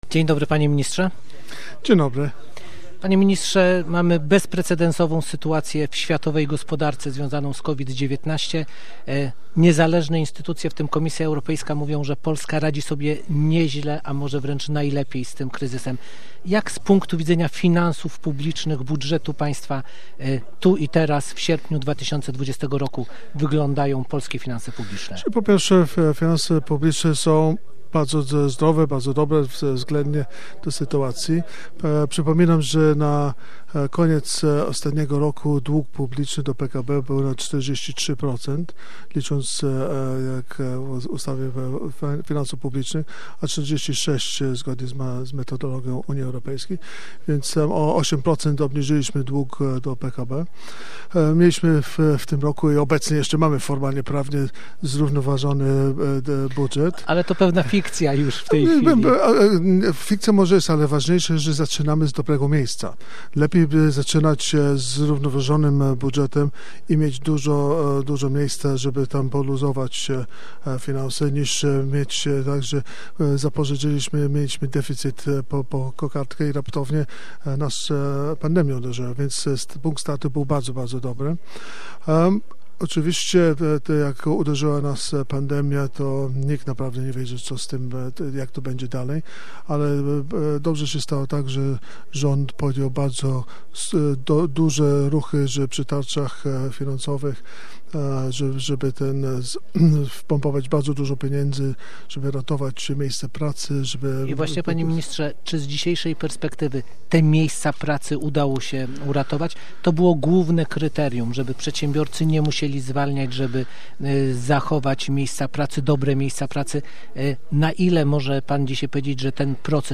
Jak będzie wyglądać sierpień w budżecie państwa i czemu deficyt nie musi oznaczać zjawiska negatywnego? Czy polska gospodarka okazała się być odporna na kryzys? O budżecie państwa, walce ze skutkami pandemii oraz sytuacji w jakiej znalazła się Polska rozmawialiśmy z Gościem Dnia Radia Gdańsk z ministrem finansów.